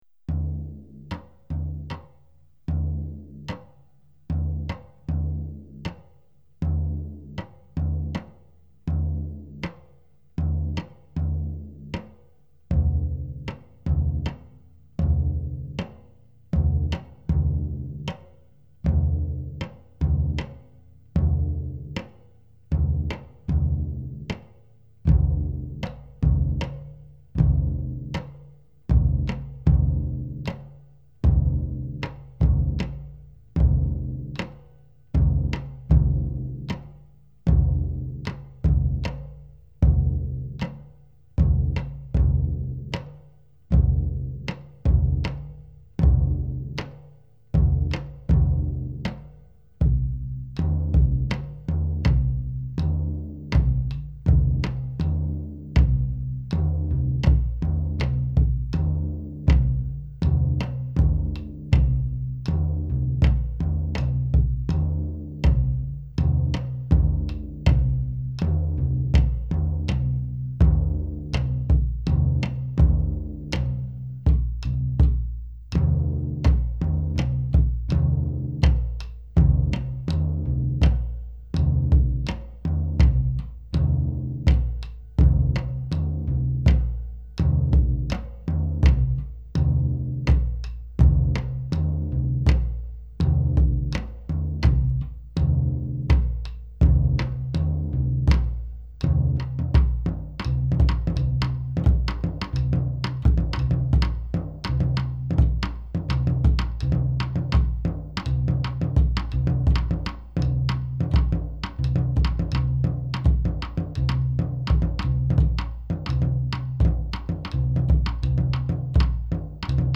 This rhythm is very deceptive. It has power.